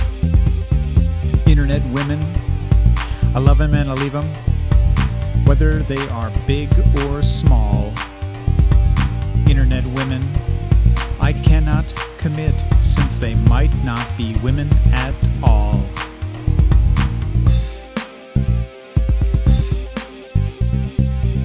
So I started creating musical settings with loops for my own works.
All samples are reduced quality versions of the full audio available on the CD.
Spoken Word